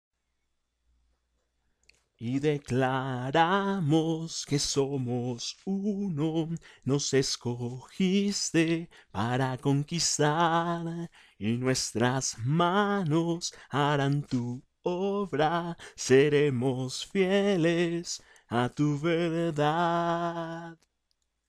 Voz Hombre Coro